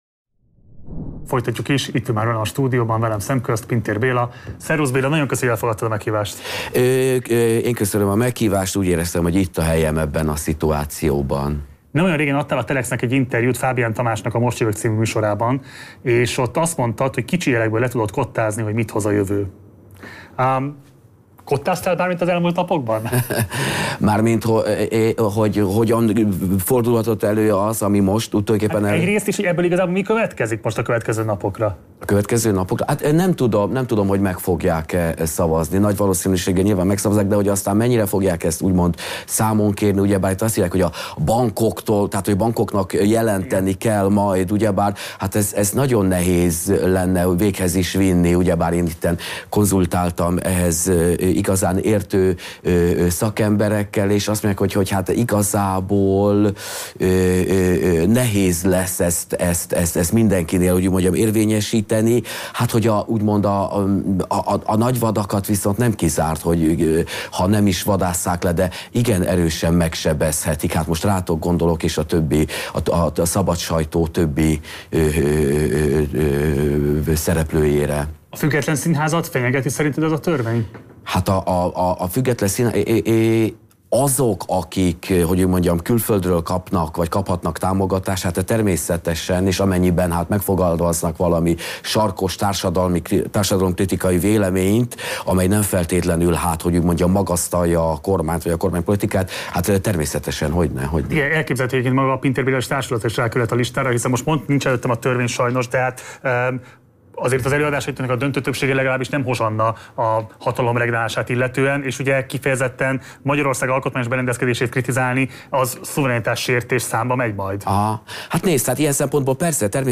Végleg ellehetetlenítené a kormány a független sajtót | Közös kiállás élőben a Kossuth térről – Partizán – Lyssna här